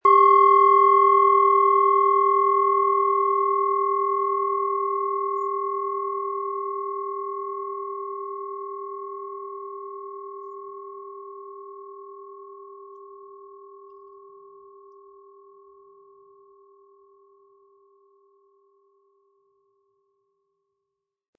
Handgearbeitete Schale mit dem Planetenton Tageston.
Ein schöner Klöppel liegt gratis bei, er lässt die Klangschale harmonisch und angenehm ertönen.
Die Aufnahme gibt den Originalton der hier gezeigten Planetenschale Tageston wieder.
SchalenformBihar
MaterialBronze